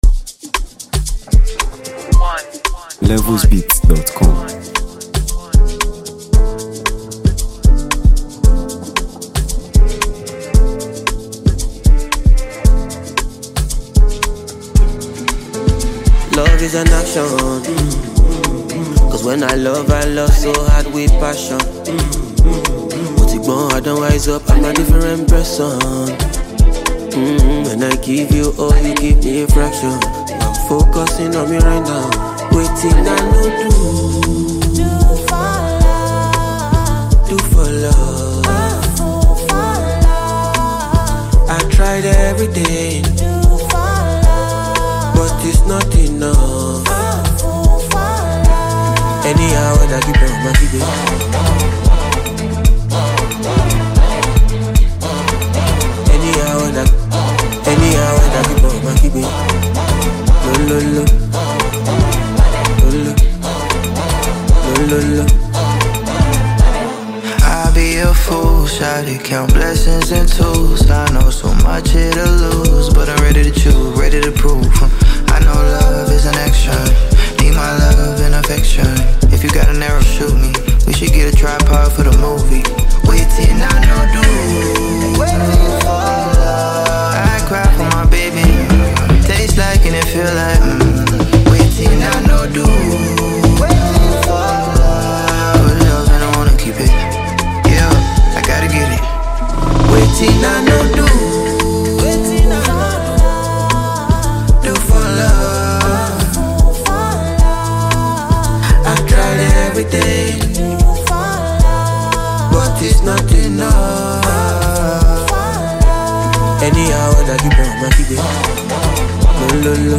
Afrobeats
soul-lifting and inspiring track
delivers smooth, expressive vocals